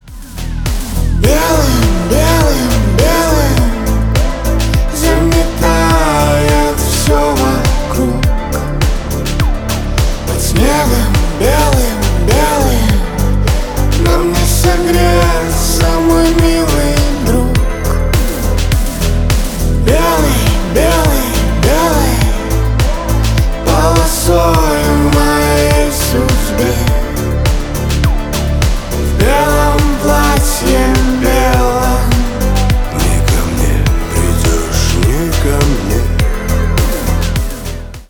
Громкая мелодия в жанре танцевальной музыки.
Танцевальные рингтоны
Клубные рингтоны